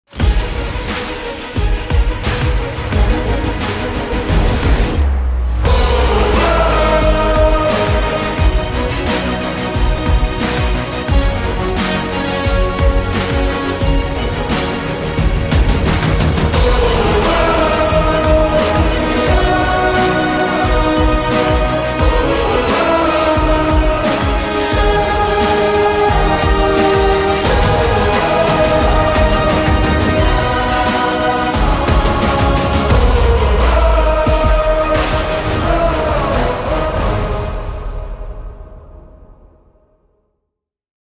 это мощная и вдохновляющая композиция